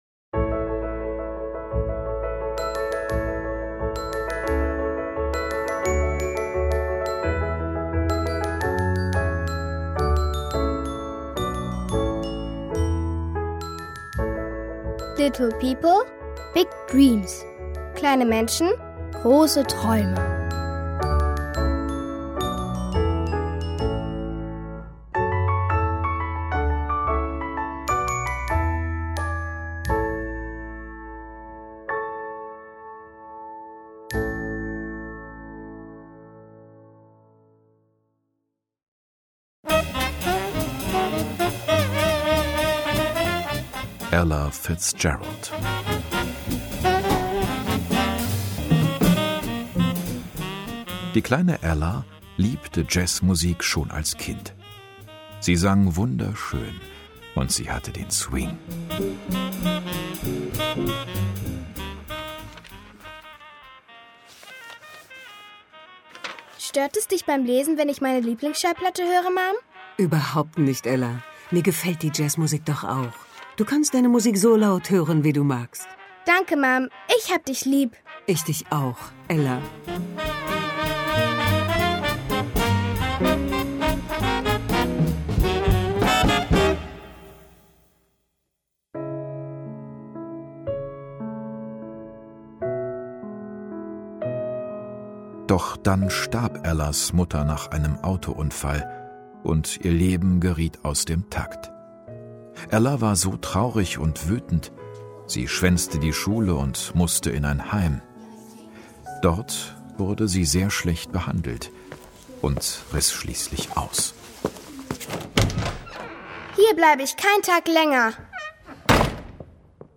Little People, Big Dreams® – Teil 2: Ella Fitzgerald, Jane Austen, Coco Chanel, Muhammad Ali Hörspiele